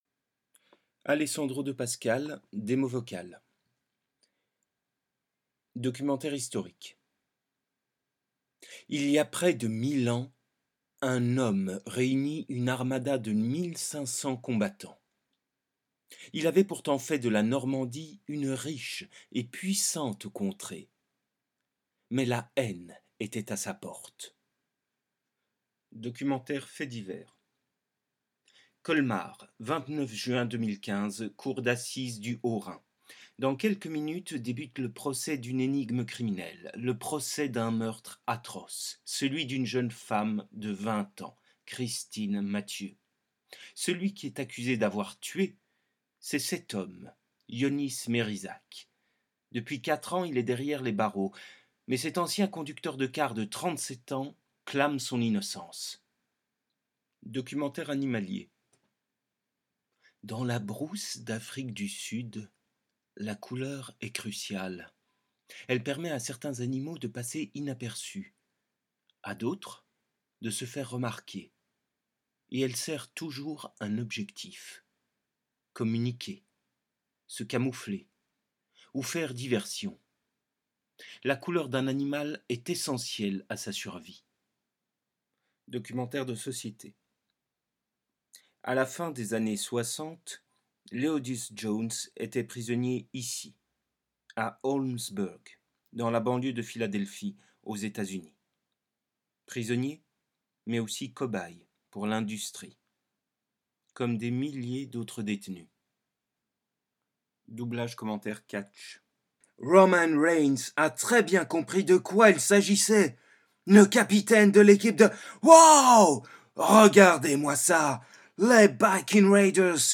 Essais voix off
Voix off
20 - 48 ans - Baryton